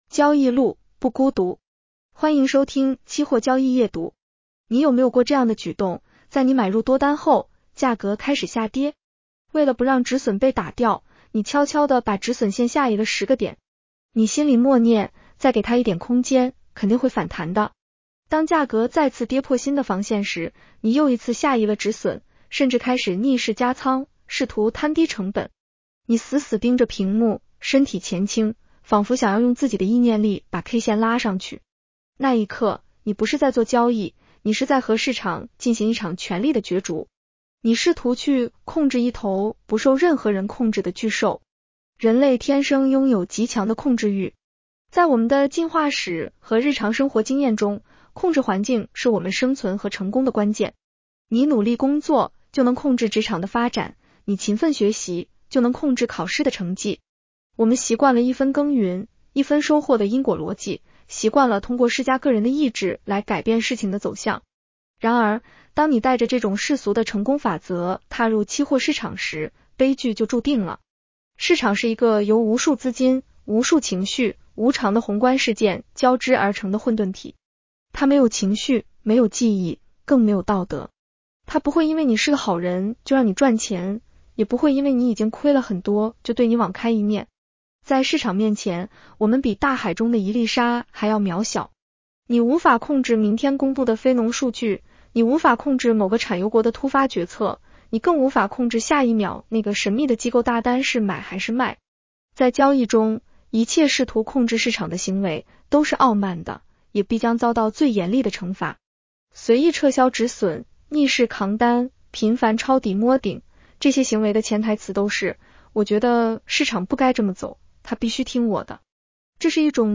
女声普通话版 下载mp3 交易路，不孤独。
（AI生成） 风险提示及免责条款：市场有风险，投资需谨慎。